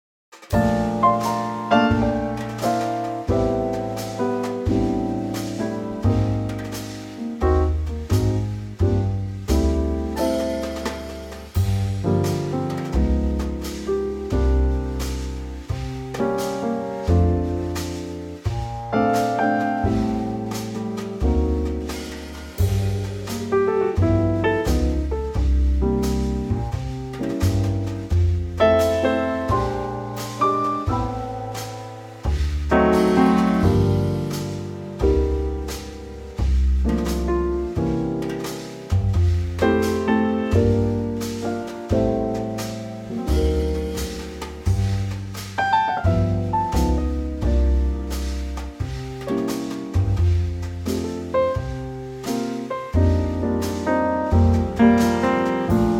key F
key - F - vocal range - C to E
Trio arrangement